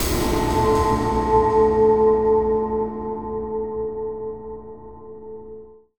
Synth Impact 20.wav